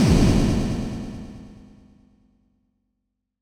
snd_closet_impact.ogg